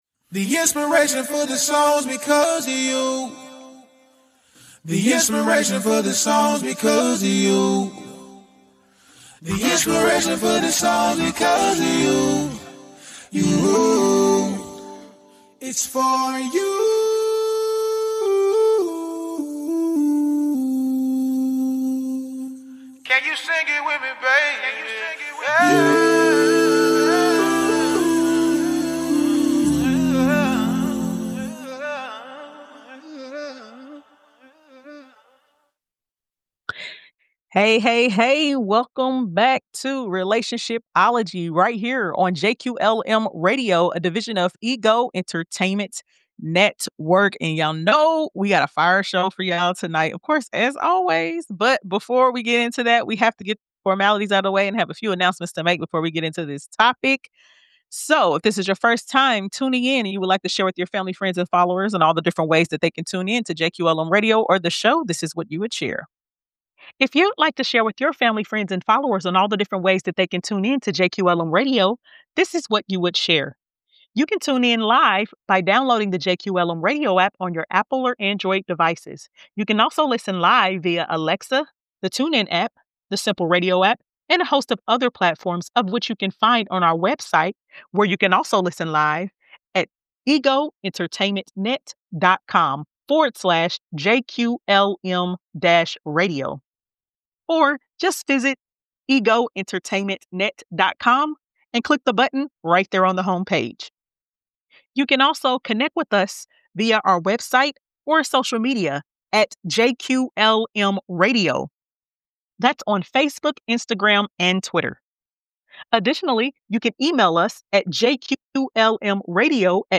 A radio talk show (formerly known as 'Pillow Talk') hosted by a duo that dives into the ups and downs of relationships and focuses on understanding the art of all types of relationships, from romance to friendship, to business, and more. The show features guests, giveaways, and more.